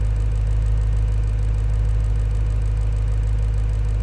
rr3-assets/files/.depot/audio/Vehicles/v8_02/v8_02_idle.wav
v8_02_idle.wav